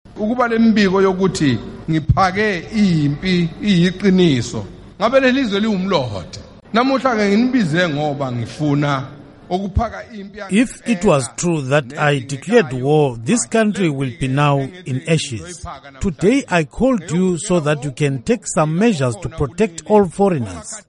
King Zwelithini Speaking at An Imbizo